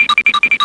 SFX综艺常用 (33)音效下载